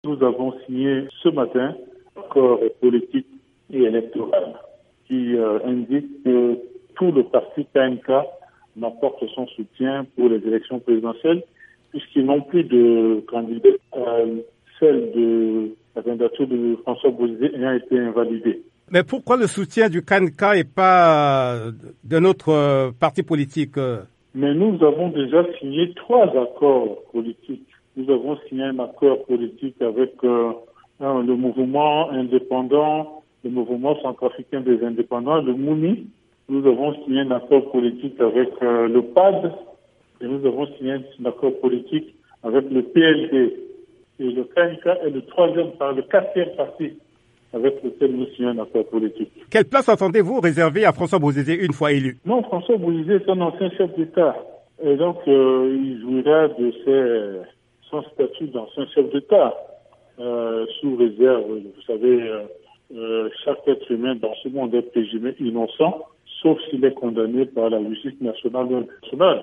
"Nous avons signé ce matin un accord politique et électoral qui indique que tout le parti KNK (Ndlr : Kwa na Kwa, parti de François Bozizé) m’apporte son soutien puisqu’il n’a plus de candidat; la candidature de Bozizé ayant été invalidée ", affirme dans une interview à VOA Afrique Anicet Georges Dologuele.